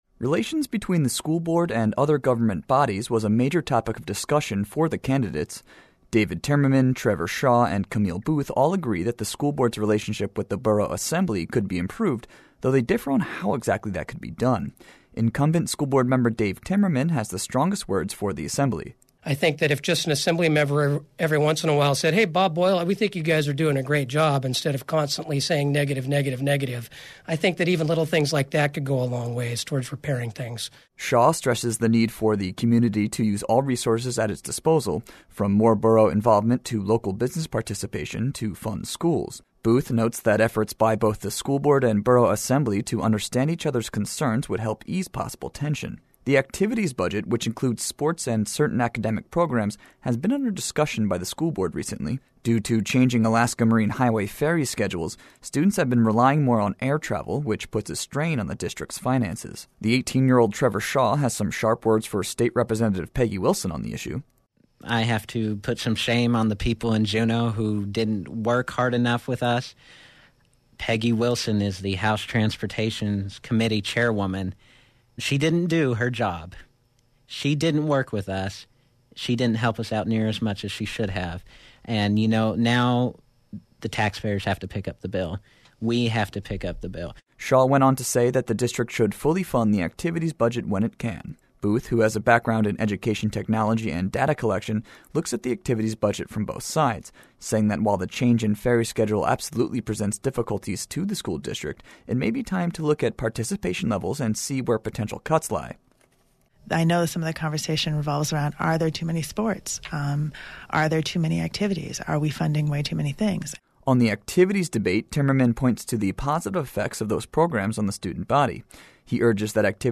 Candidates seeking a seat on the Ketchikan Gateway Borough School Board joined KRBD for a forum Monday to discuss issues facing the school district.